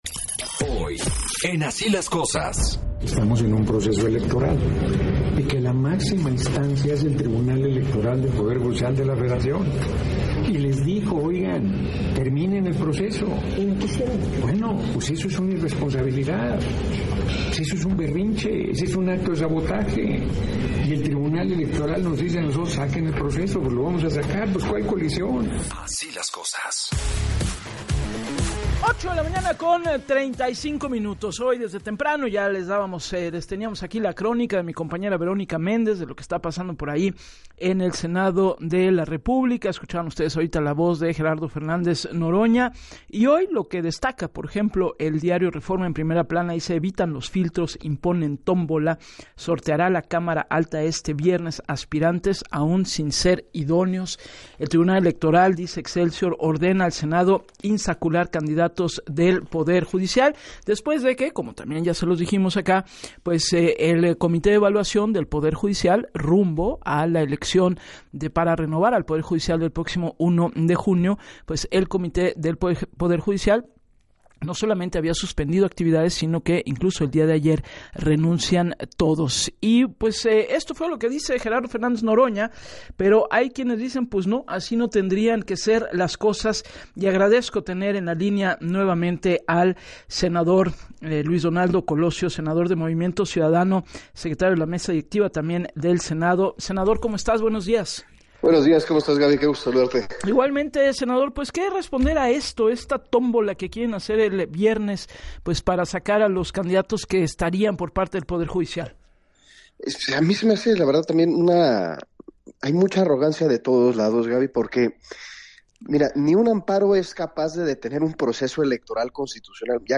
“Un amparo no puede detener un proceso electoral, aunque no nos guste”, pero a partir de su renuncia de ayer el Comité de Evaluación del Poder Judicial no está cumpliendo y al final habrá elección, esto le afecta directamente a la gente, porque los perfiles van a ser insaculados de manera directa y puede llegar cualquier perfil”, señaló el senador en entrevista para “Así las Cosas” con Gabriela Warkentin en donde externó “preocupa la falta de seriedad con que está tomando esta elección judicial”.